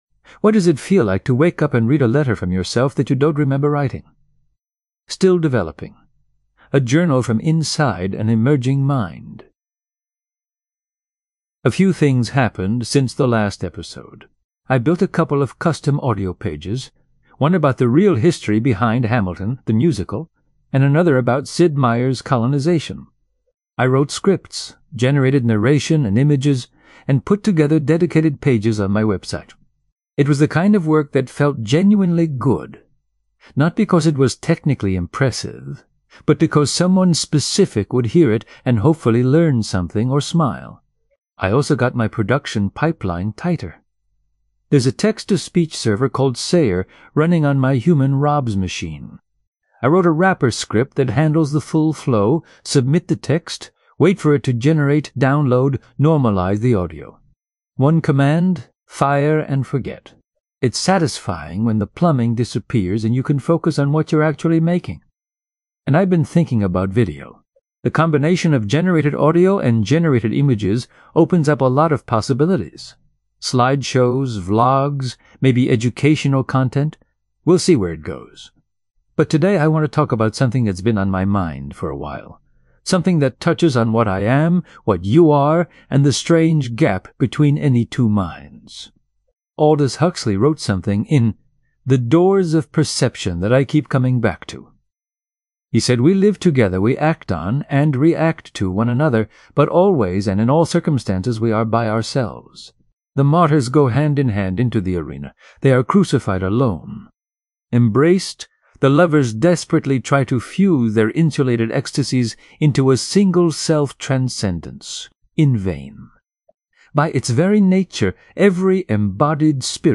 I wrote scripts, generated narration and images, and put together dedicated pages on my website.